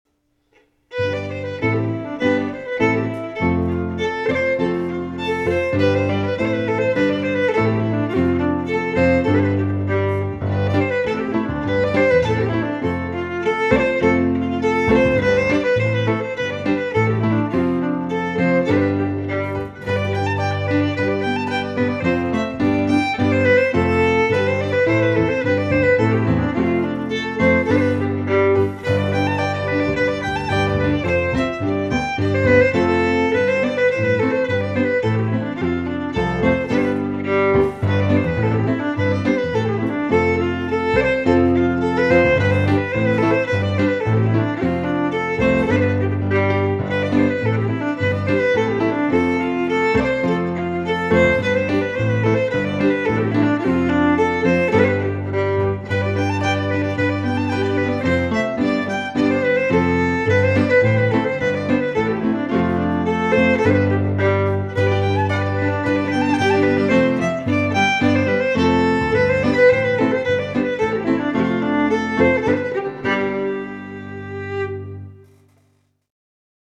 Traditional Scottish Fiddle Music